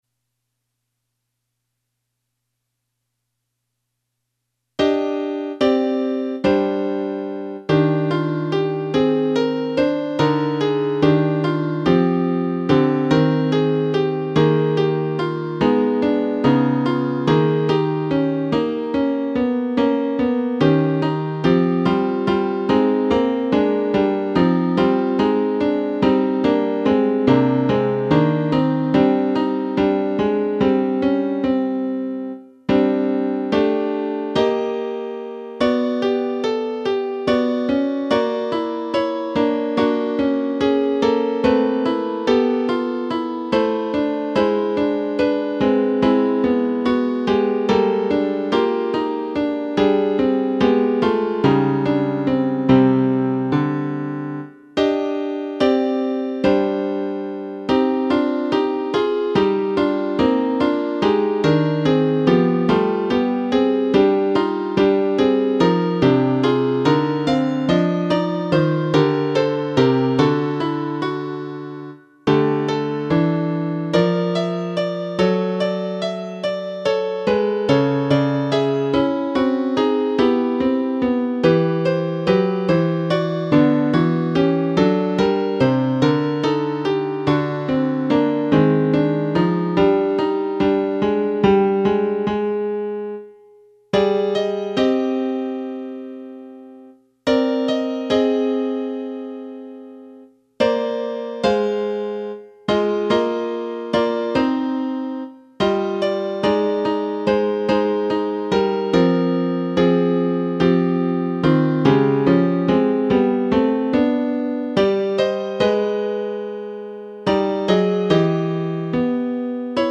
(SAB a cappella)